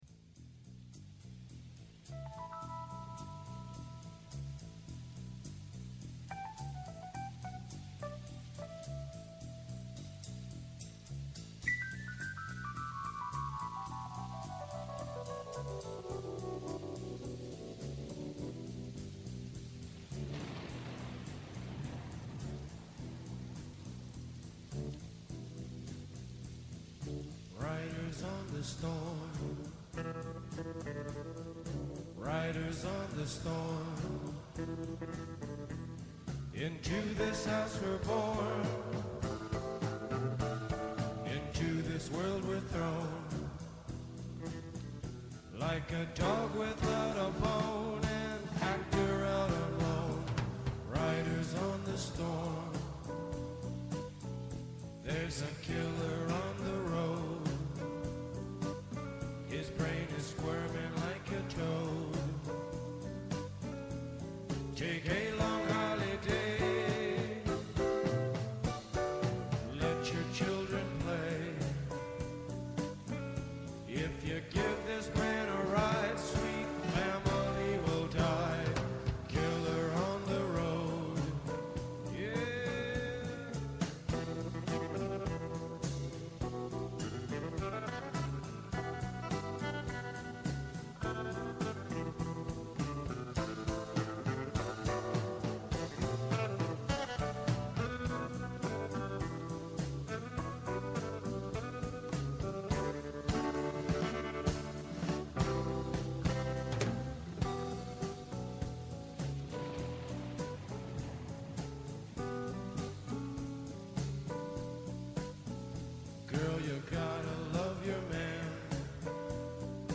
-Ο Κωνσταντίνος Λετυμπιώτης, Κυβερνητικός Εκπρόσωπος Κύπρου
-Η Ελίζα Βόζεμπεργκ, Ευρωβουλευτής ΝΔ